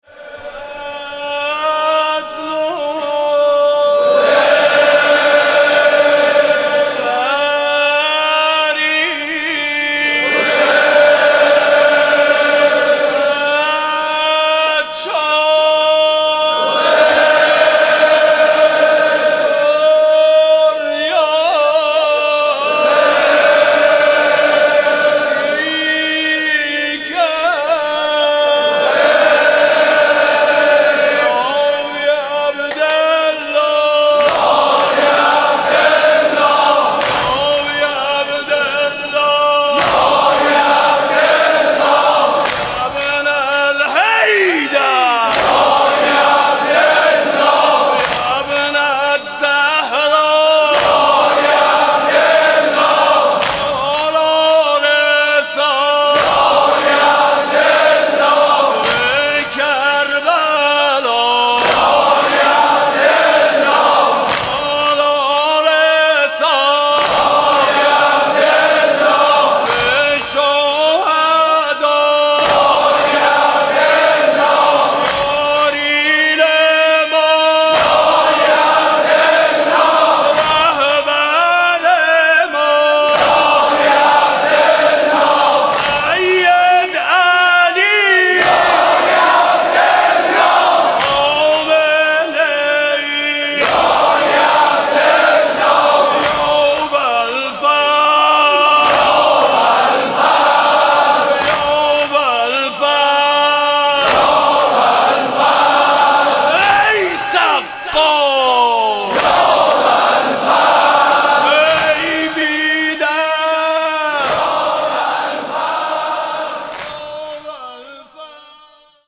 صوت مراسم شب دوم محرم ۱۴۳۷ هیئت میثاق با شهدا ذیلاً می‌آید: